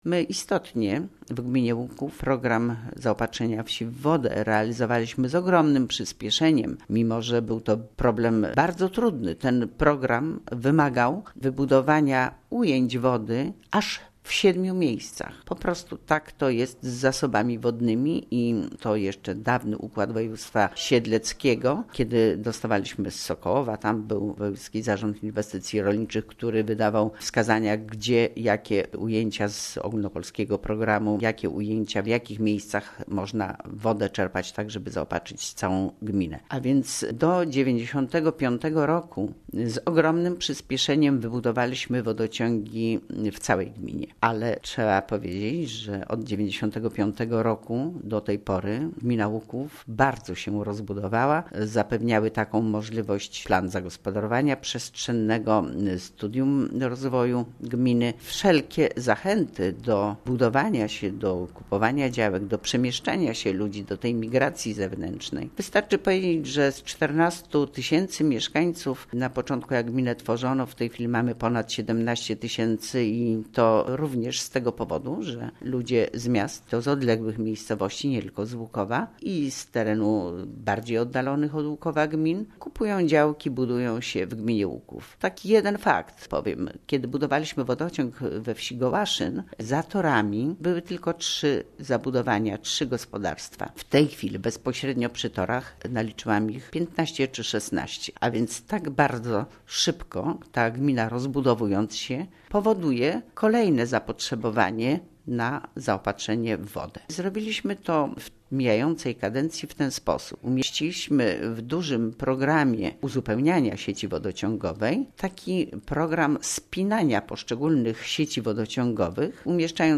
O szczeg�ach informuje: W�jt Gminy �uk�w Kazimiera Go�awska